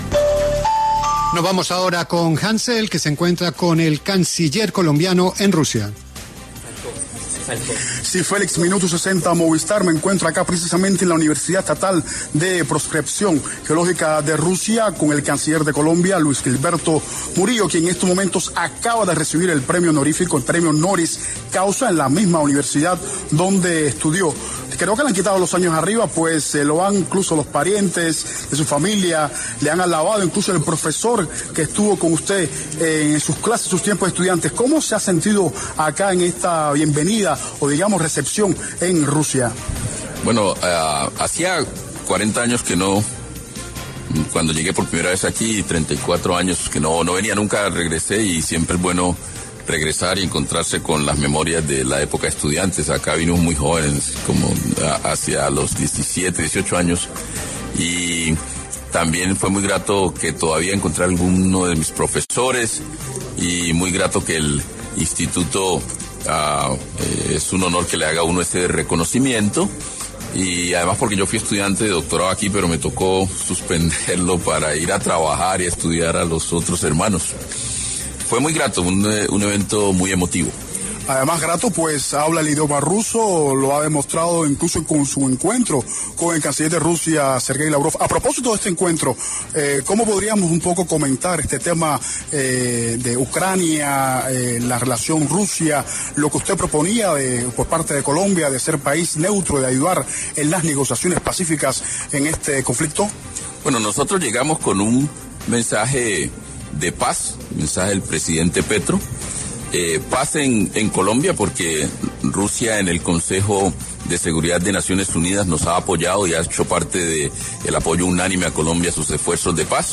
El canciller Luis Gilberto Murillo, habló en La W sobre este reconocimiento por parte de la Universidad de Geología de Rusia.
El ministro pasó por los micrófonos de La W con Julio Sánchez Cristo y reveló que hace 34 años no regresaba a esta universidad, lo que hizo aún más especial el reconocimiento.